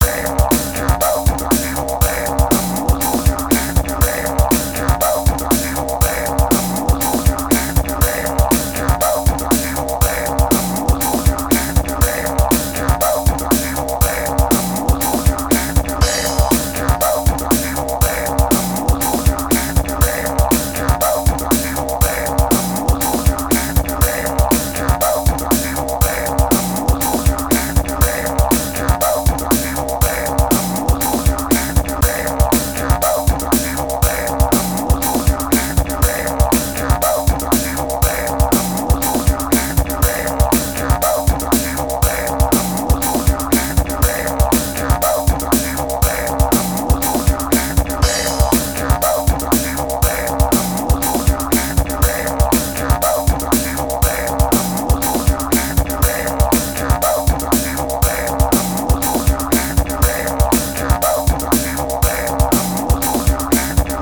Easy-Listening